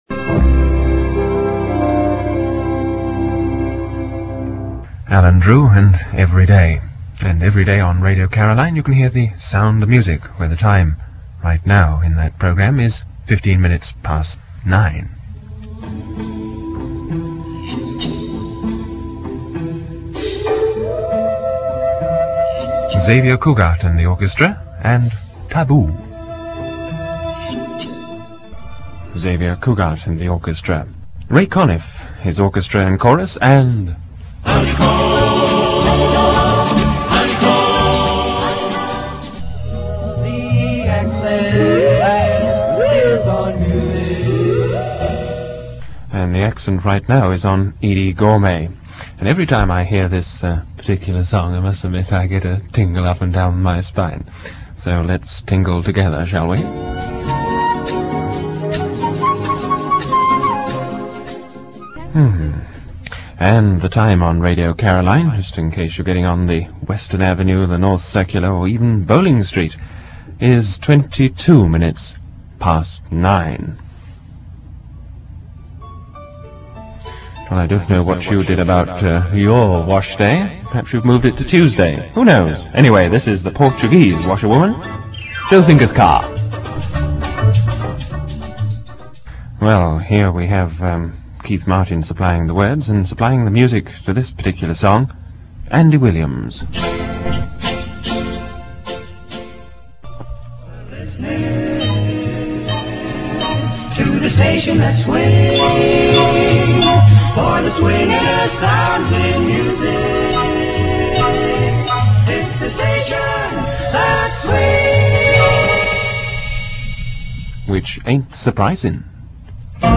a studio recording